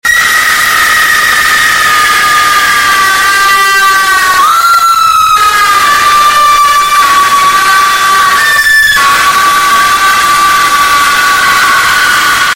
ASMR for sleep